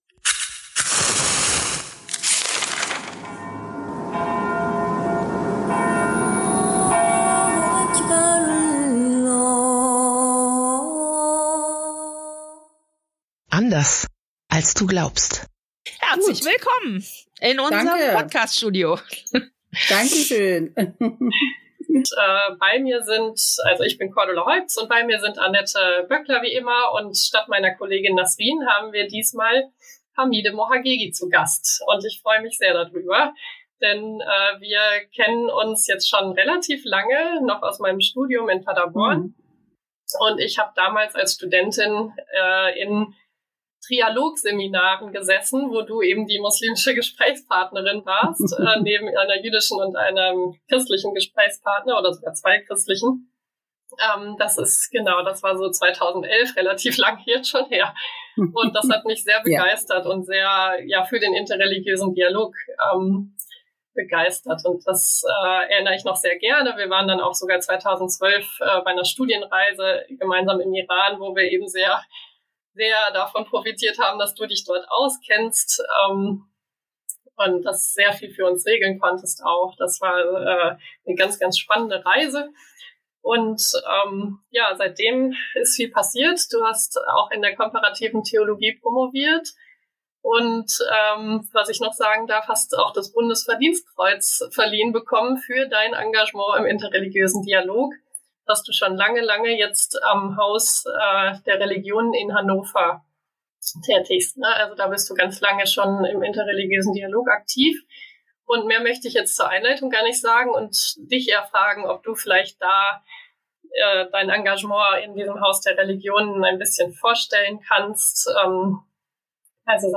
Ein Gespräch über pädagogische Konzepte, Ausstellungskonzepte, und Erfahrungen in der Begegnung mit religiösen Menschen.